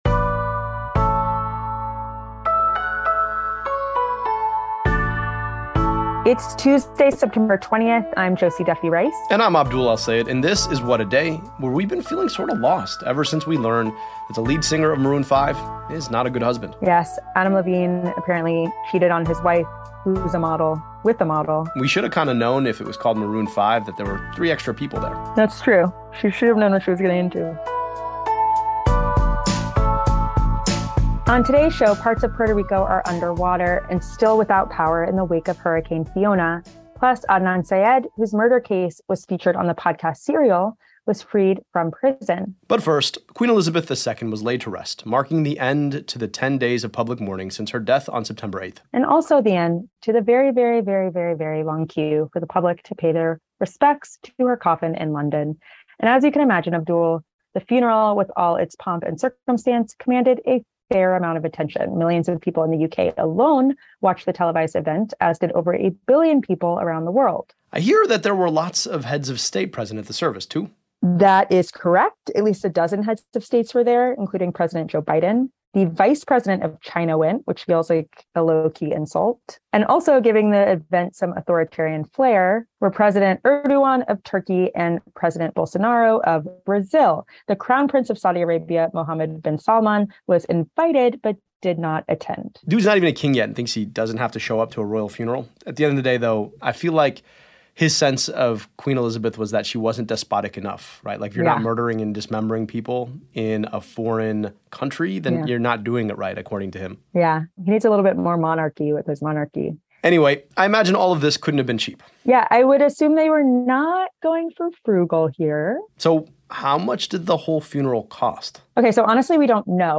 Dr. Abdul El-Sayed, host of Crooked’s “America Dissected,” tells us, there's still work to be done.